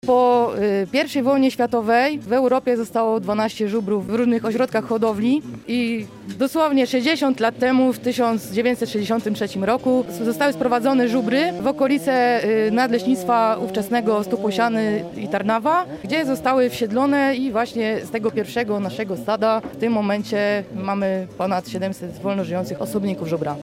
Dzisiaj kamper Radia Biwak dojechał do Lutowisk na Dzień Żubra.